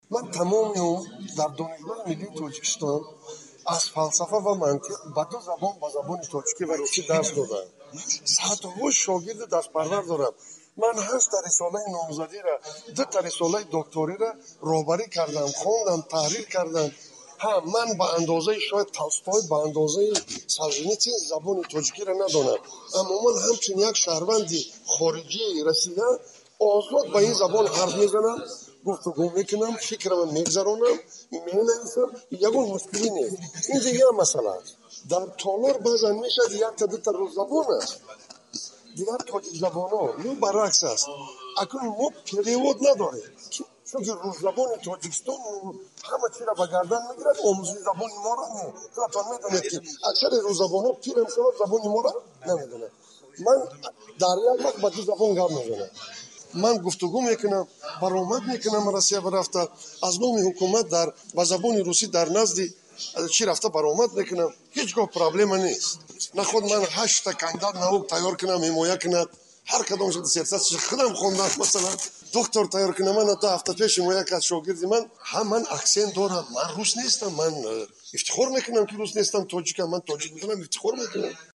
Вазири маориф ва илми Тоҷикистон ба даҳҳо интиқоди интернетӣ дар мавриди сатҳи русидонии ӯ вокуниш кард ва гуфт, "ҳамагуна талоши беобрӯ кардани як узви ҳукумат беобрӯ кардани ҳукумат аст". Нуриддин Саид рӯзи 21-уми феврал дар суҳбат бо Радиои Озодӣ гуфт, шояд ба андозаи Толстой ва Солжинитсин забони русиро надонад, "аммо бо ин забон хуб гап мезанад".
Садои вазирро дар инҷо бишнавед: